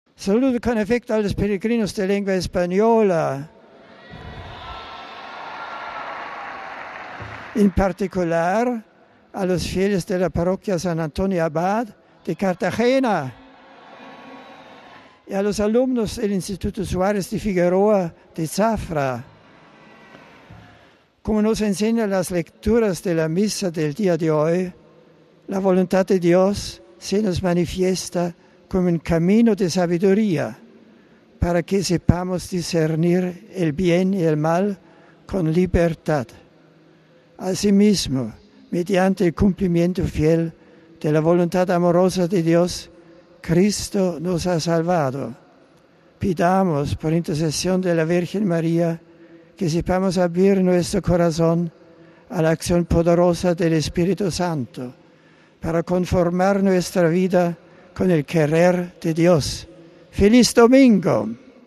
Finalmente este ha sido el saludo del Santo Padre en español para lo peregrinos de nuestra lengua presentes en la plaza de san Pedro: